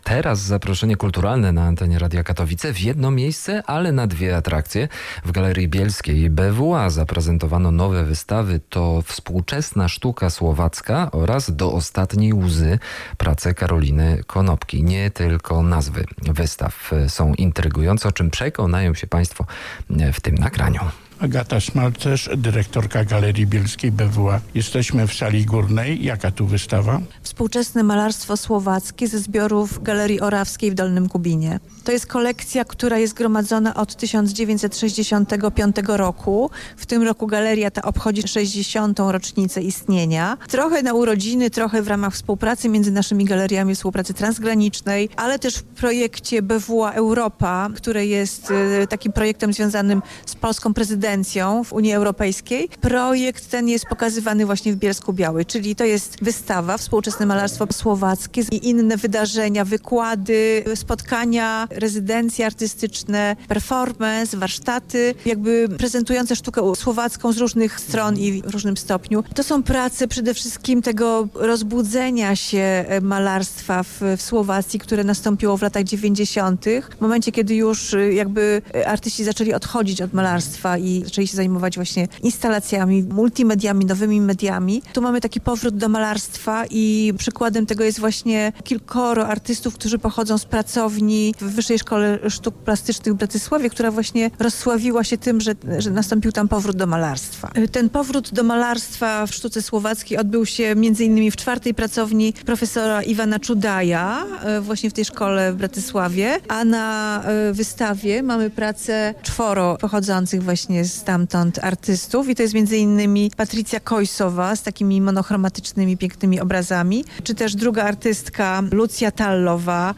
audycja o wystawach w Galerii Bielskiej BWA